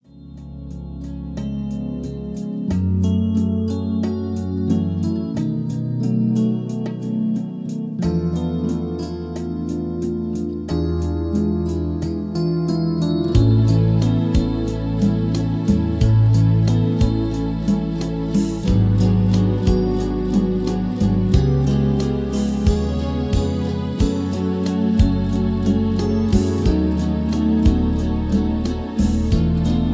Backing Tracks for Professional Singers.